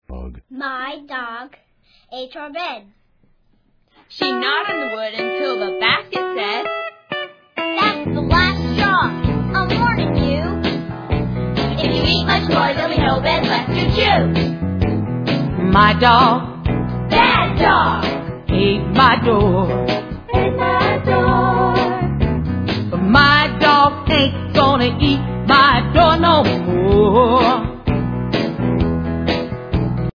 From bouncy and boisterous to warm and furry